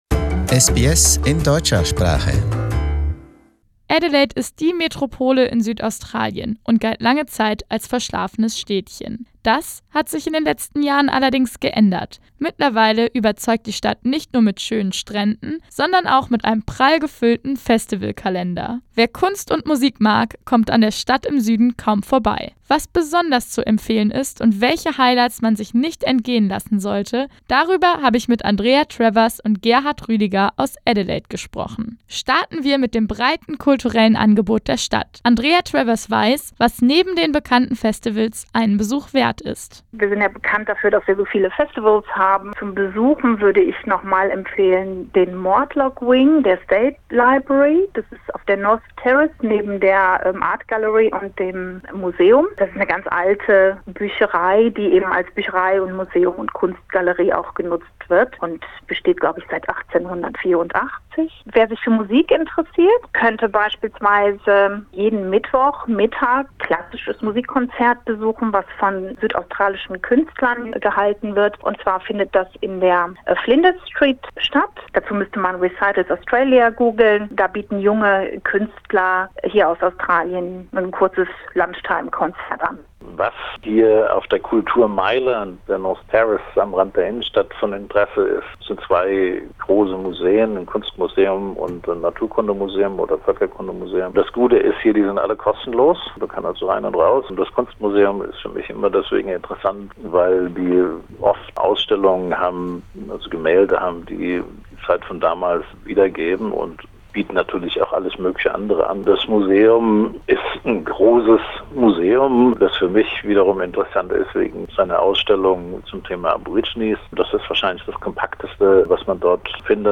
SBS Radio spoke to two Germans and asked them for their favorite hidden places in Adelaide.